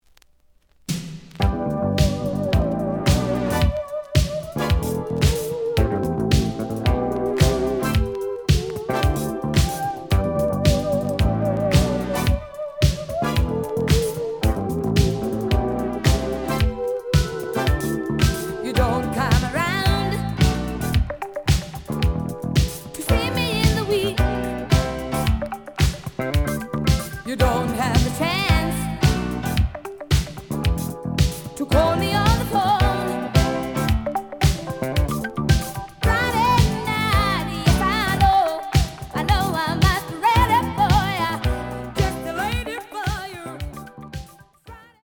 The audio sample is recorded from the actual item.
●Genre: Disco
Edge warp. But doesn't affect playing. Plays good.)